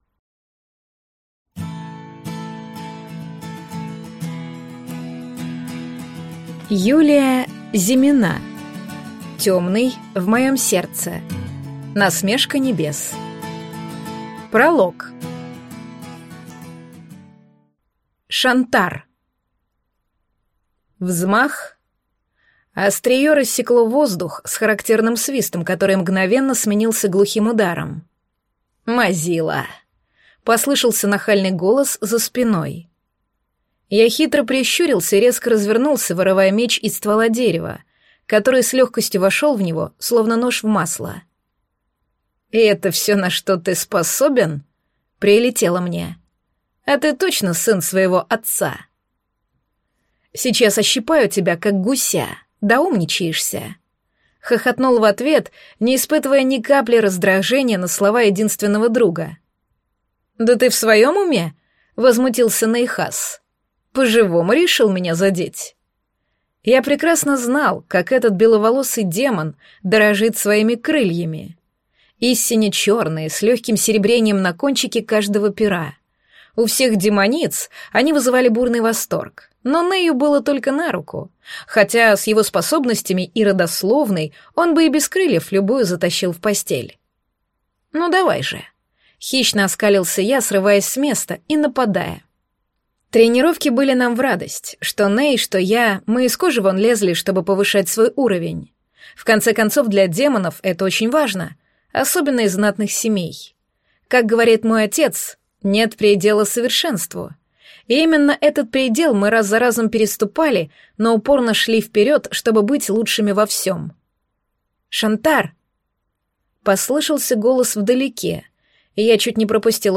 Аудиокнига Темный в моем сердце: Насмешка небес | Библиотека аудиокниг